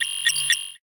Index of /phonetones/unzipped/Google/Android-Open-Source-Project/notifications/ogg